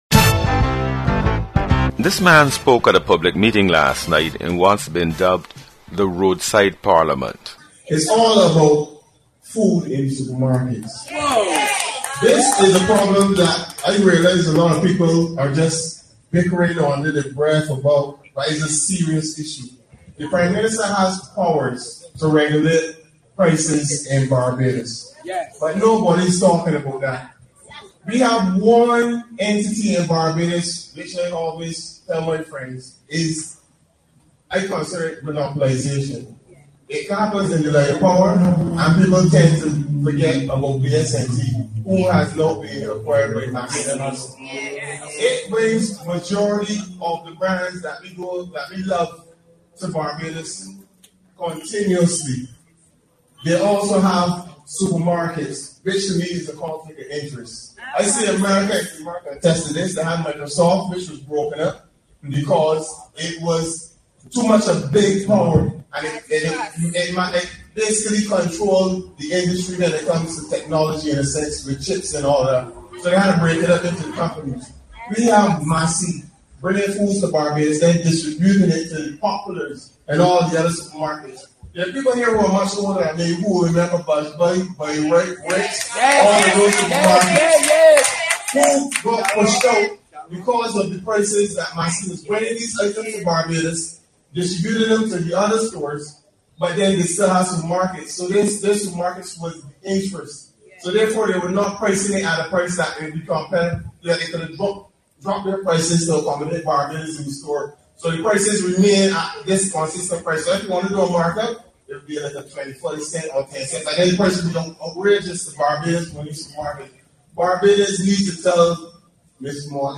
Conversations from the "Road Side Parliament" on the high cost of food in the country.